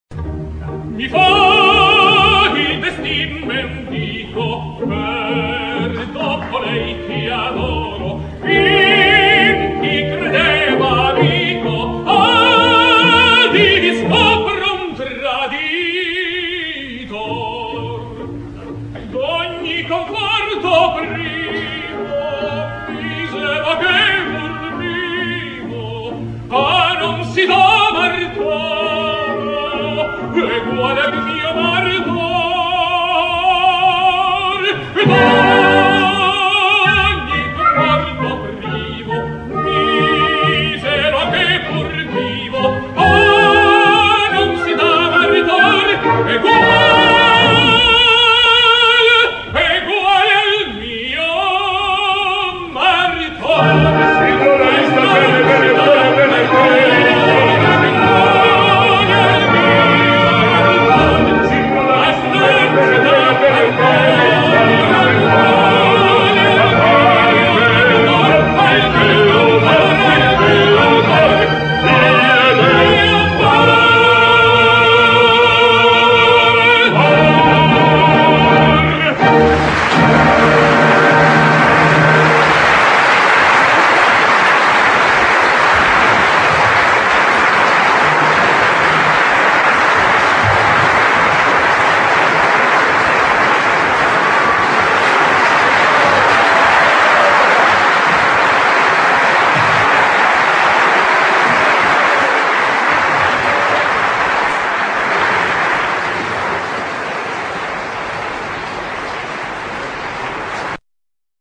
Alfredo Kraus sings Don Pasquale: